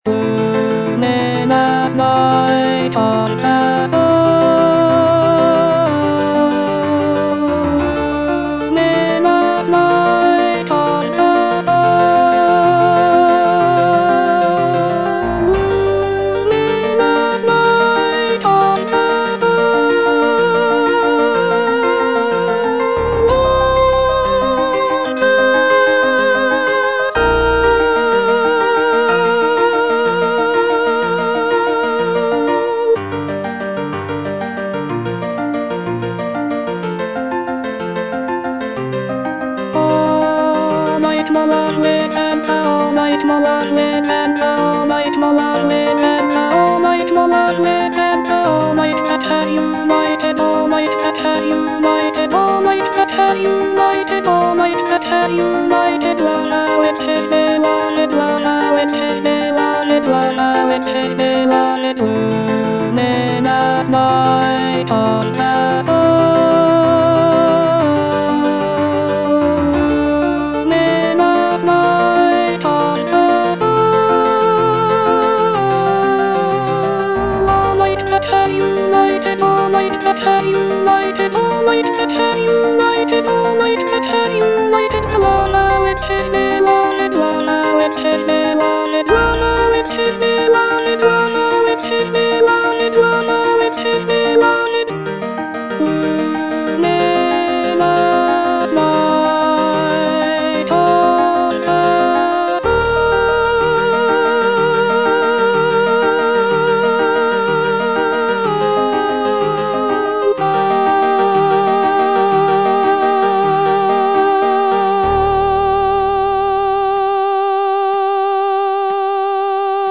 (alto I) (Voice with metronome) Ads stop